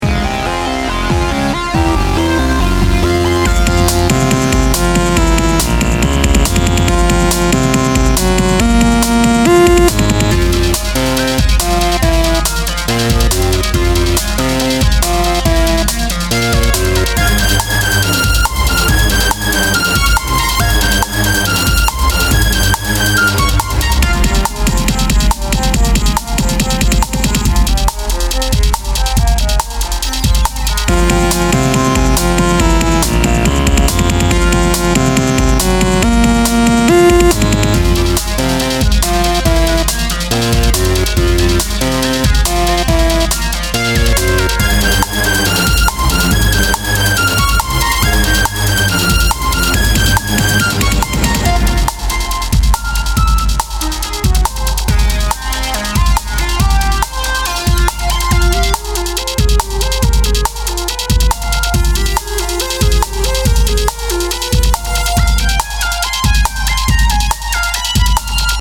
A space-sounding battle scene or RPG boss battle.